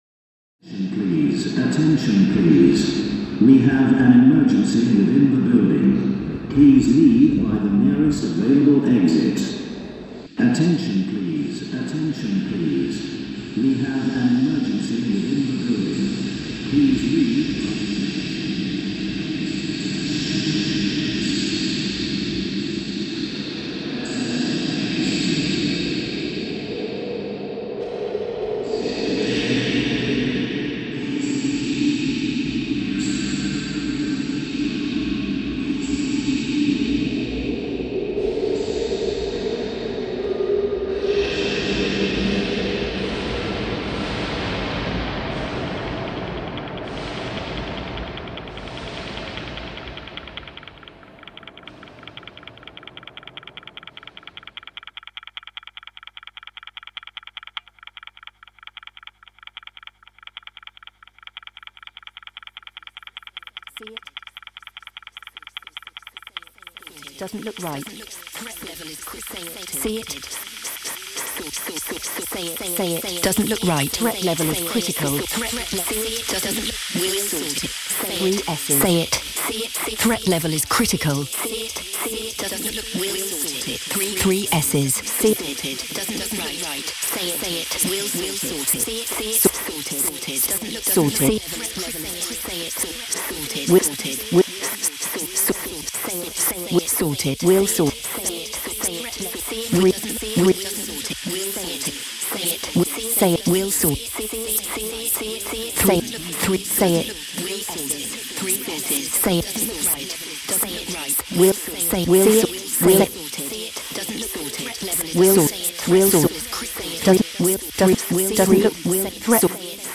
Westgate Centre alarm